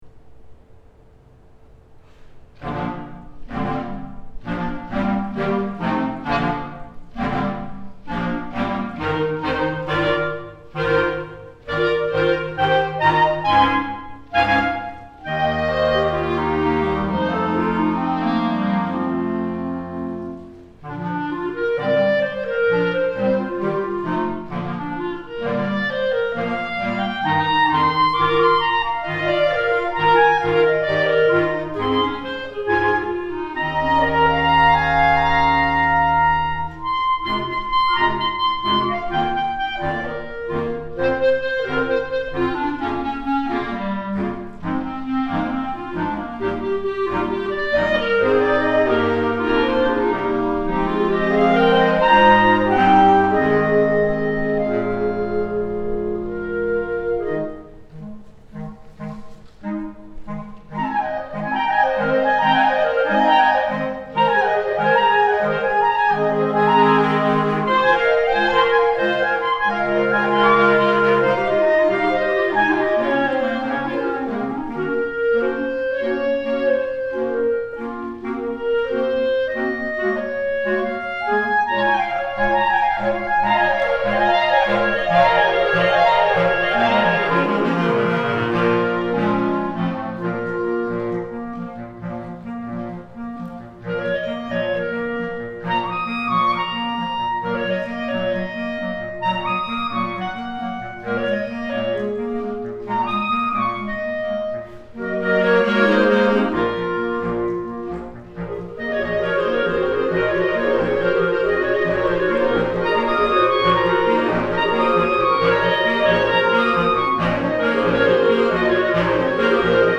3 Clarinets in B-Flat
2 Bass Clarinets
Contrabass Clarinet